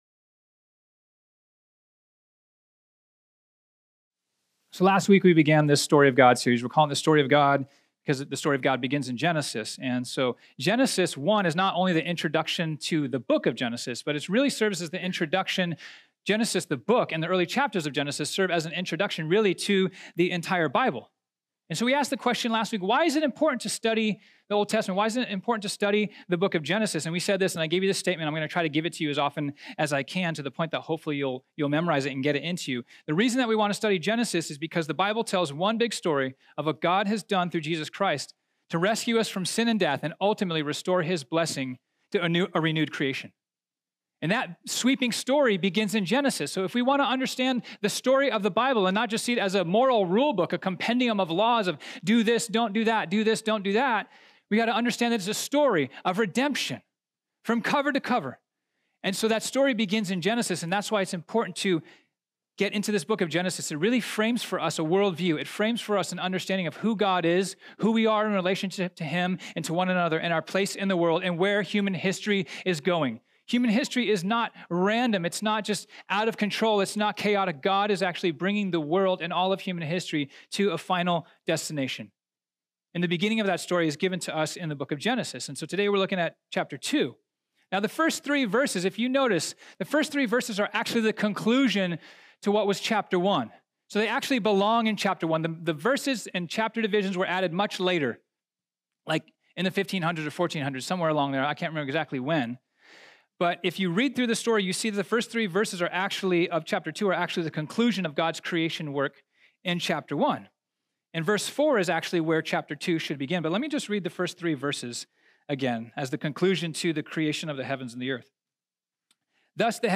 This sermon was originally preached on Sunday, January 13, 2019.